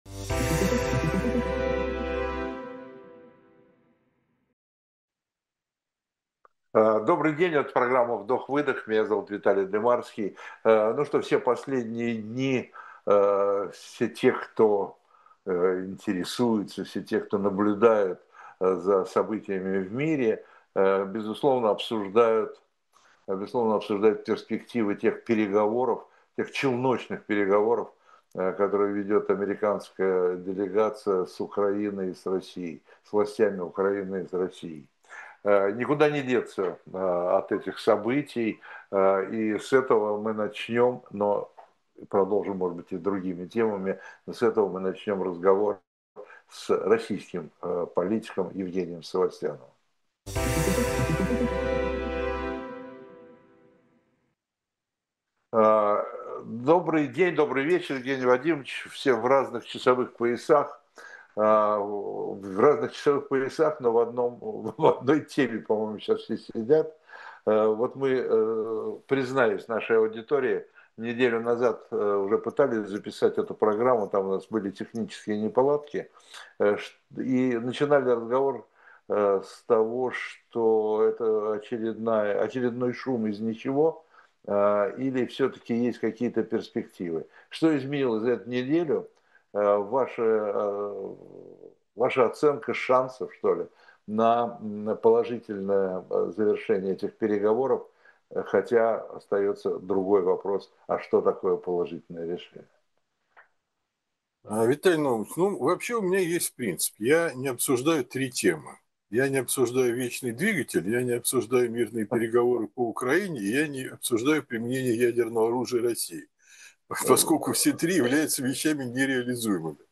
Эфир ведёт Виталий Дымарский
Политик Евгений Савостьянов — гость программы «Вдох-выдох» на канале Ходорковский Live. Почему обсуждать переговоры России и Украины — то же, что и обсуждать вечный двигатель? Остановка военных действий — цель Трампа, но вред для Путина и Си Цзиньпина.